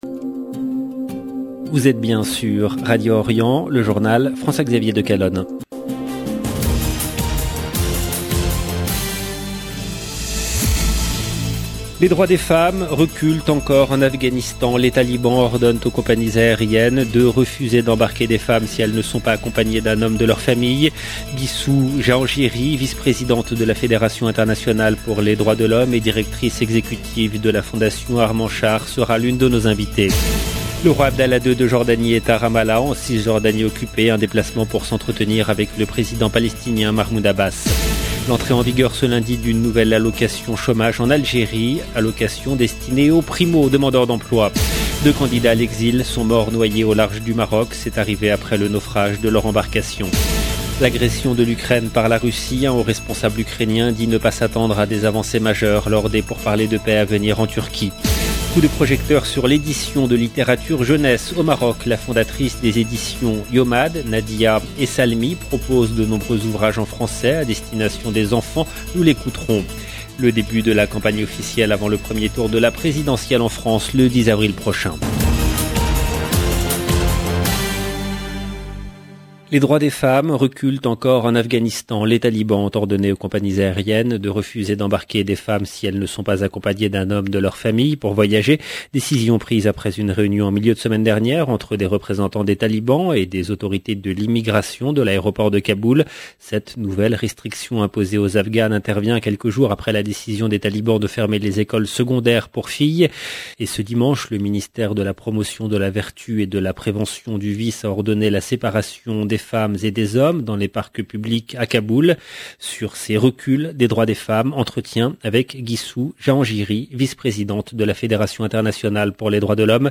LE JOURNAL DU SOIR EN LANGUE FRANCAISE DU 28/03/22 LB JOURNAL EN LANGUE FRANÇAISE